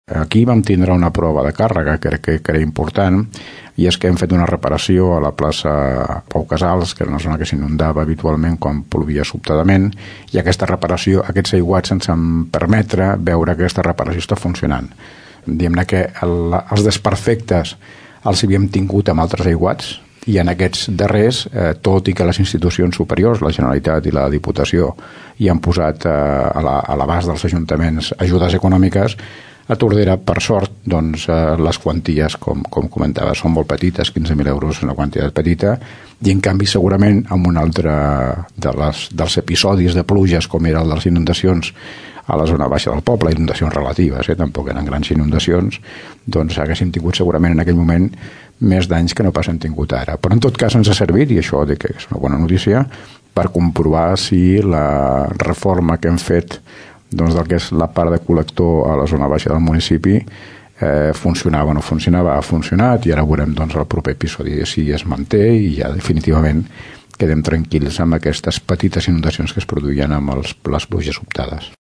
L’Alcalde del nostre municipi, Joan Carles Garcia, diu que les pluges del mes d’octubre van servir per comprovar que algunes de les actuacions que es van fer a la part baixa del poble, que quan plovia s’inundava molt, han funcionat.